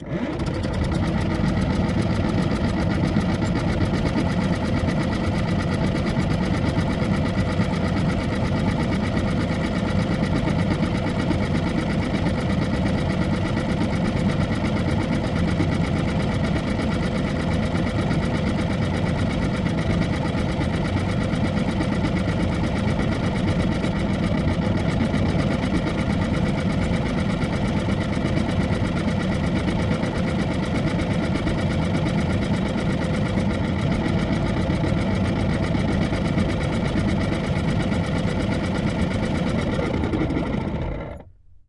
沃尔沃加热器 " 沃尔沃马达 1
描述：一辆老沃尔沃旅行车的加热器旋转起来，运行，然后停止。 它非常明显，而且，坏了。 2010年9月用Zoom H4录制的。 没有添加任何处理。
Tag: 加热器 电机旋 沃尔沃 抱怨